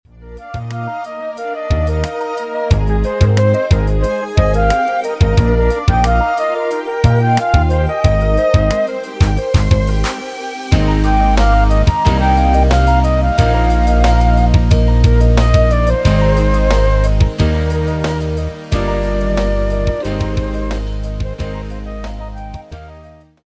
karaoké